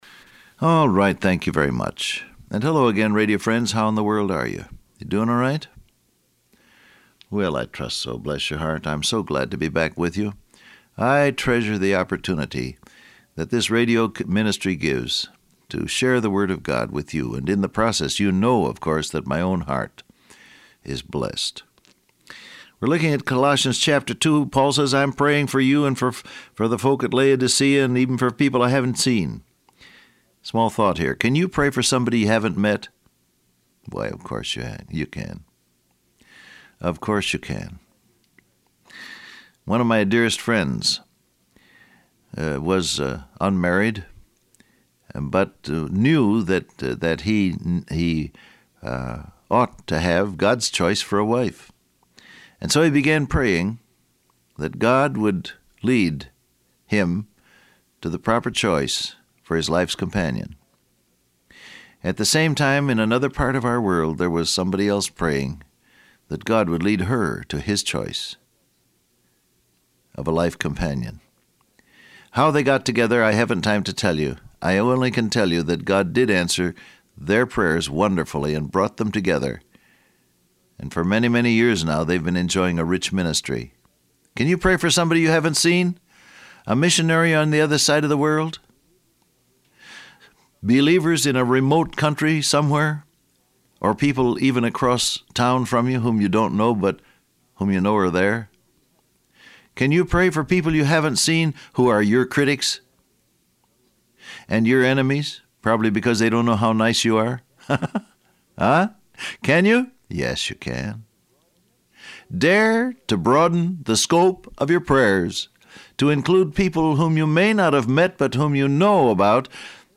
Download Audio Print Broadcast #1883 Scripture: Colossians 2:3 , Proverbs 3:5-6 Transcript Facebook Twitter WhatsApp Alright, thank you very much.